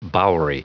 Prononciation du mot bowery en anglais (fichier audio)
Prononciation du mot : bowery